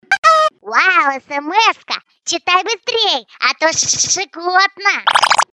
Категория: SMS-мелодии